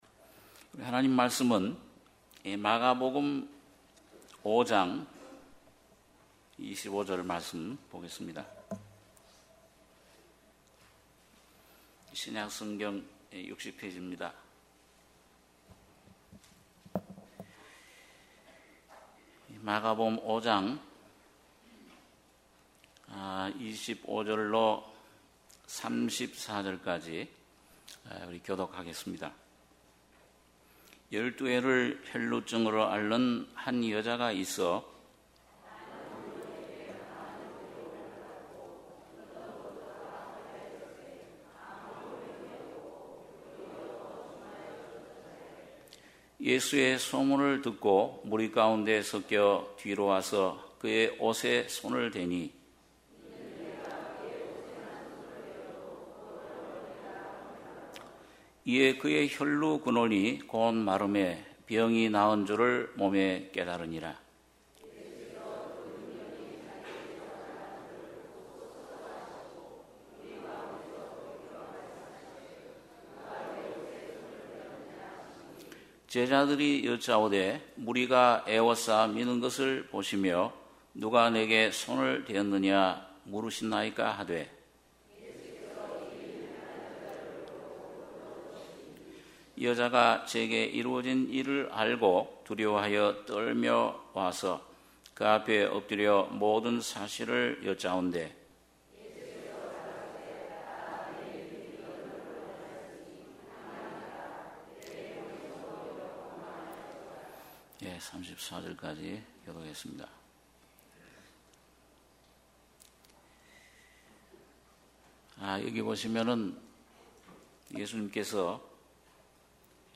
주일예배 - 마가복음 5장 25절-34절